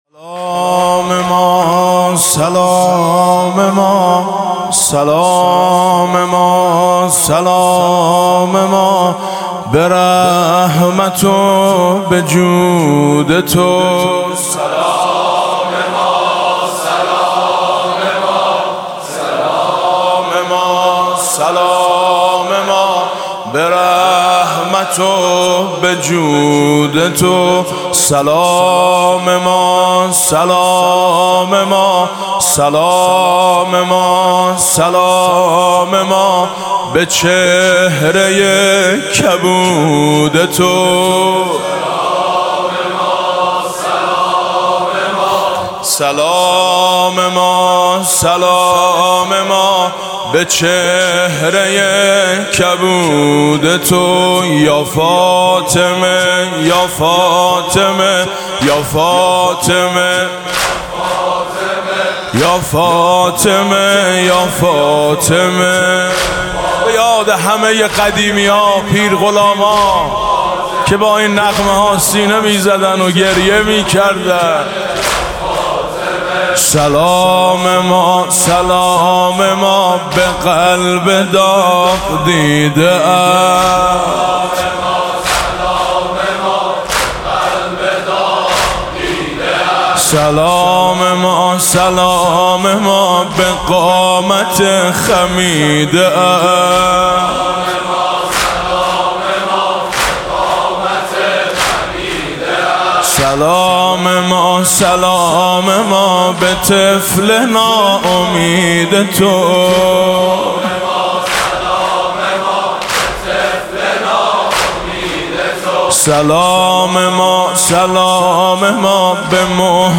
برچسب ها: مرثیه سرایی ، شهادت حضرت فاطمه (س) ، مداحی اهل بیت